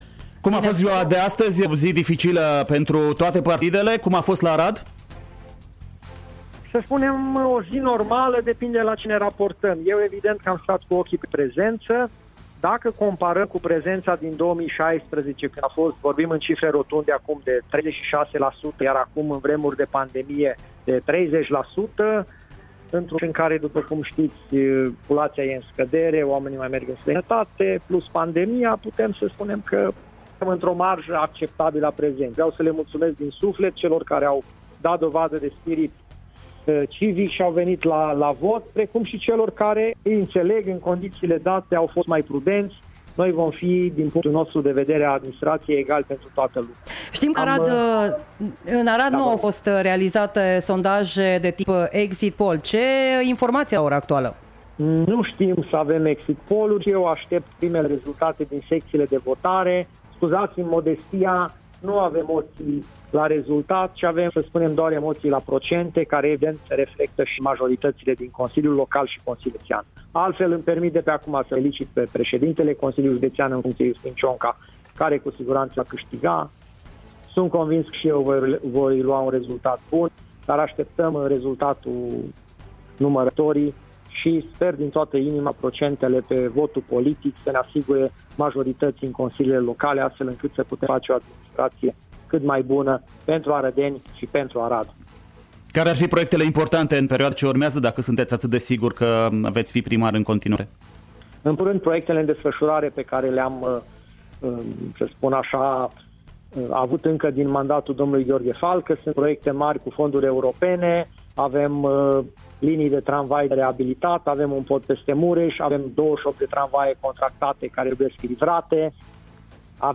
A fost în direct la câteva zeci de minute după închiderea votării, în emisiunea Noaptea Albă a Alegerilor ;